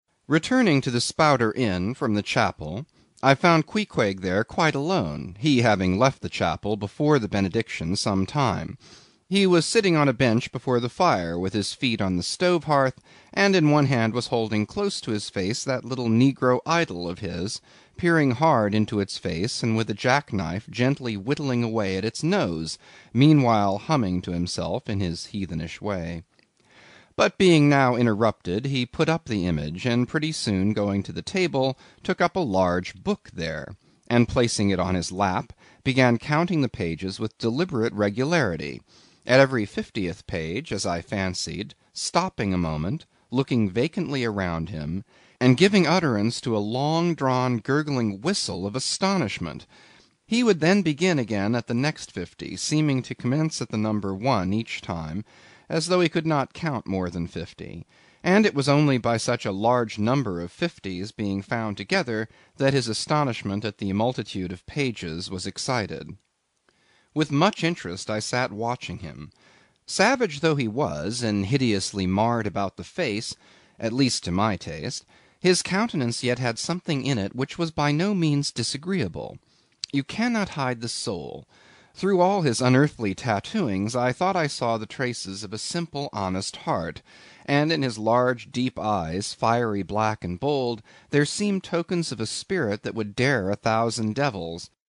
英语听书《白鲸记》第250期 听力文件下载—在线英语听力室